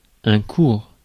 Ääntäminen
IPA: /kuʁ/